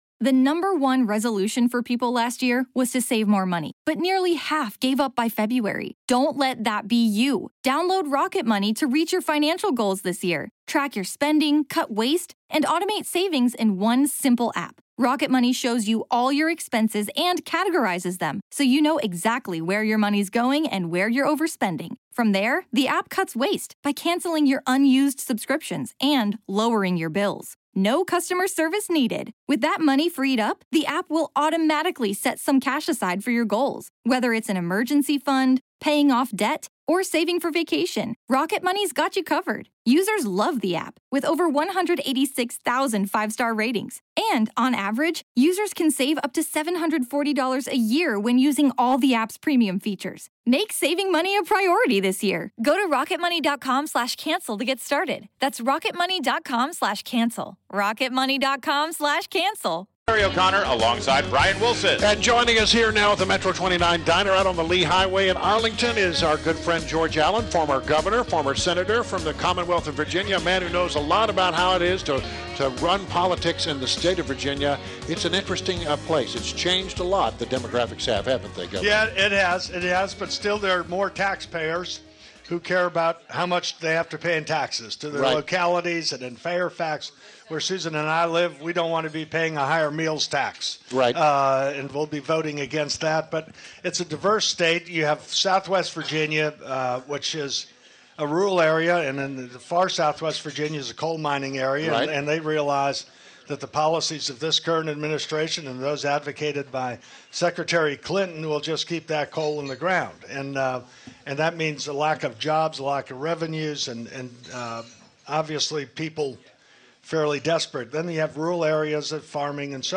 WMAL Interview - GEORGE ALLEN - 11.04.16
INTERVIEW – GEORGE ALLEN – former Virginia Governor and Senator – discussed the election and Trump’s chances in Virginia.